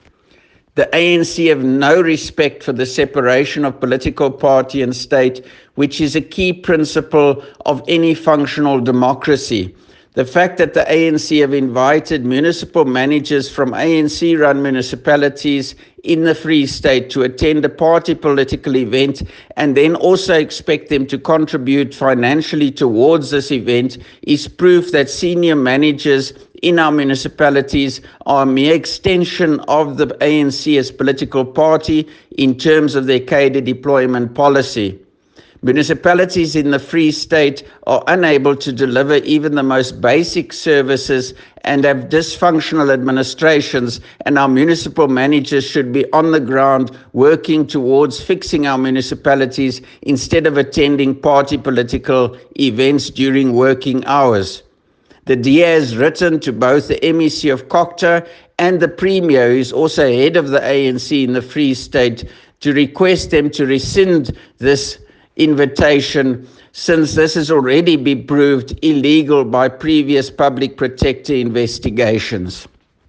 Afrikaans soundbites by Roy Jankieslohn MPL and